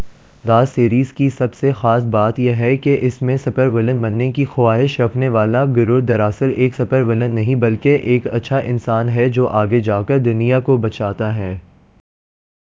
Spoofed_TTS/Speaker_14/103.wav · CSALT/deepfake_detection_dataset_urdu at main